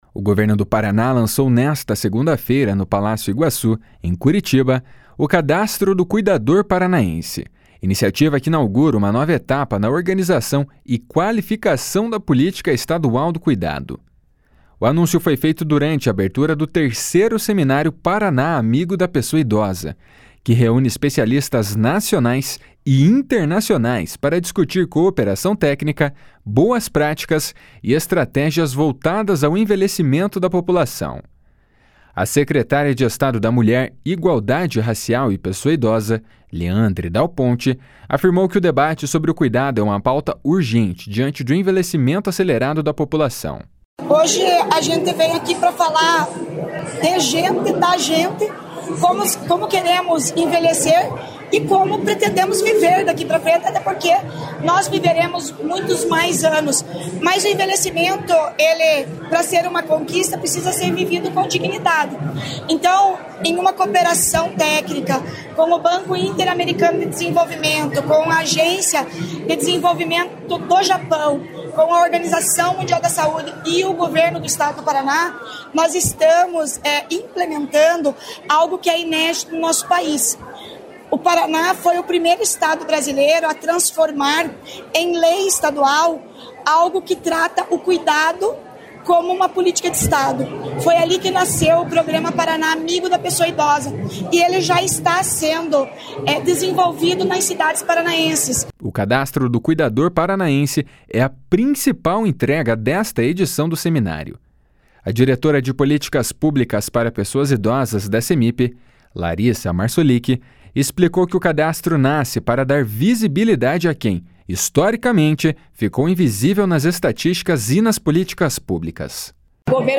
O Governo do Paraná lançou nesta segunda-feira, no Palácio Iguaçu, em Curitiba, o Cadastro do Cuidador Paranaense, iniciativa que inaugura uma nova etapa na organização e qualificação da política estadual do cuidado. O anúncio foi feito durante a abertura do III Seminário Paraná Amigo da Pessoa Idosa, que reúne especialistas nacionais e internacionais para discutir cooperação técnica, boas práticas e estratégias voltadas ao envelhecimento da população.